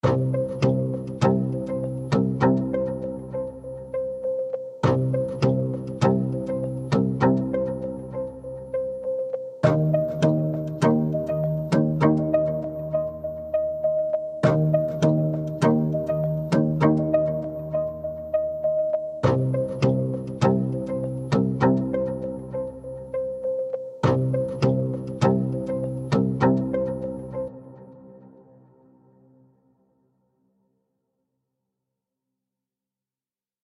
通过节奏变化进行创新。专为创造音调和节奏脉冲而精心制作。
预设由嵌入大量调制和效果的更坚韧的源循环音色组成，用于需要更强大节奏模式的情况。
MOTORS提供两层声源，每层都有一个具有3种固有变化的节奏循环。